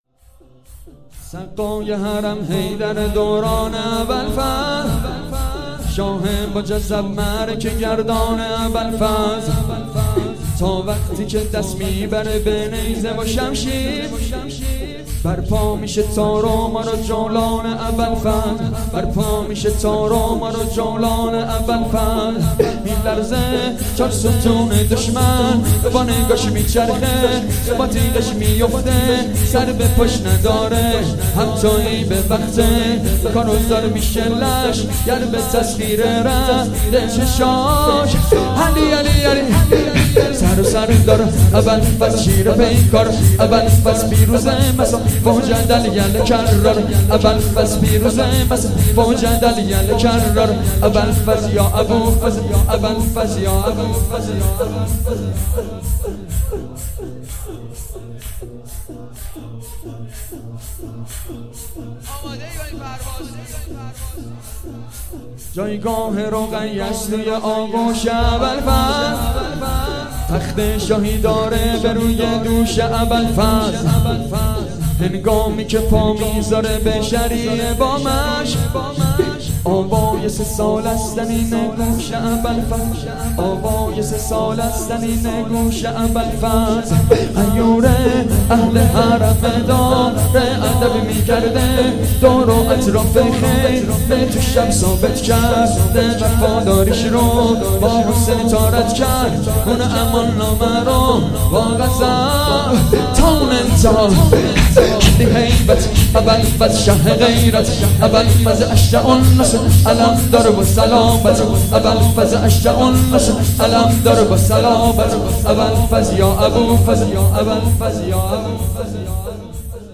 شور سقای حرم حیدر